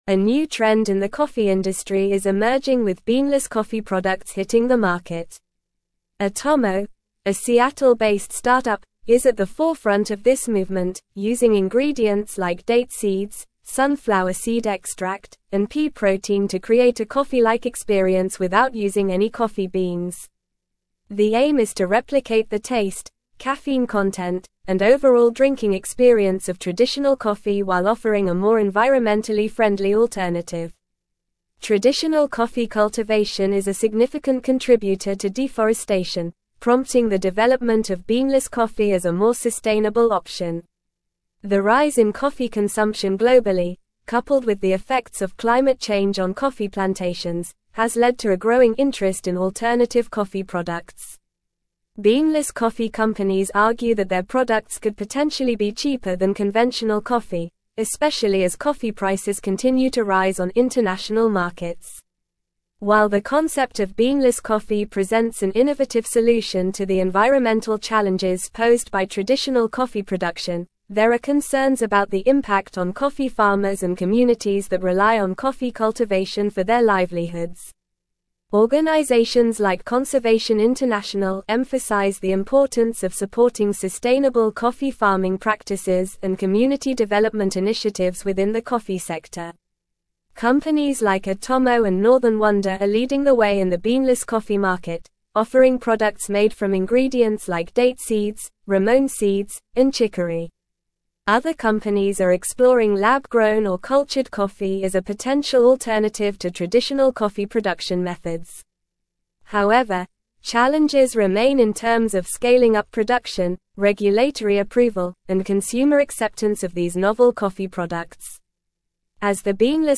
Normal
English-Newsroom-Advanced-NORMAL-Reading-Emerging-Trend-Beanless-Coffee-Revolutionizing-the-Industry.mp3